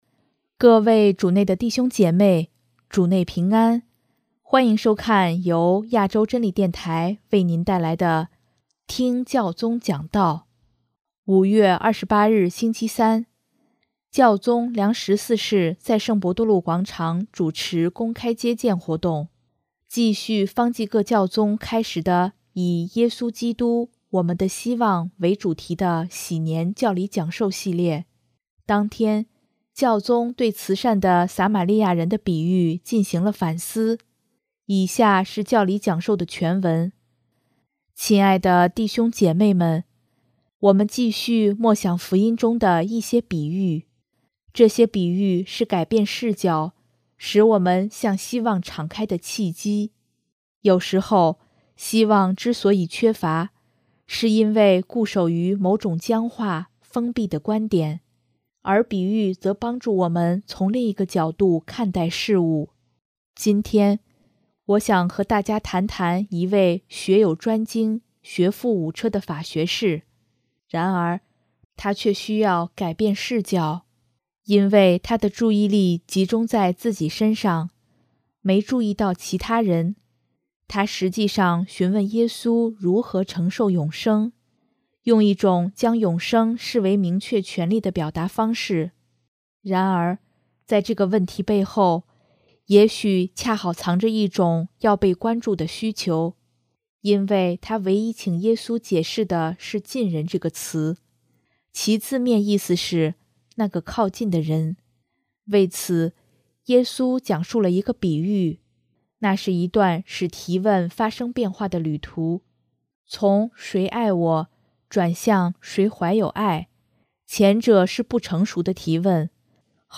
【听教宗讲道】|“爱”是愿意为他人停下脚步
5月28日星期三，教宗良十四世在圣伯多禄广场主持公开接见活动。继续方济各教宗开始的以“耶稣基督——我们的希望”为主题的禧年教理讲授系列，当天，教宗对慈善的撒玛黎雅人的比喻进行了反思。